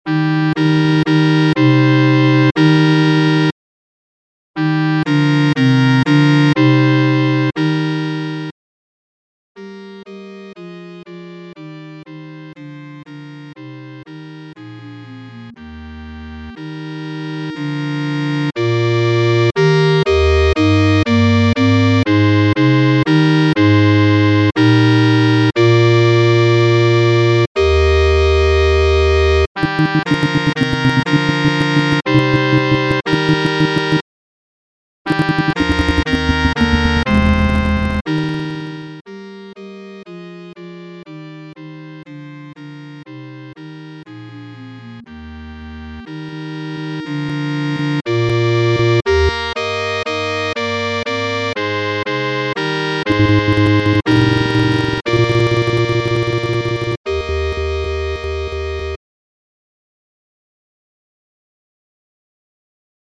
SATB (4 voices mixed) ; Choral score.
Tonality: E flat major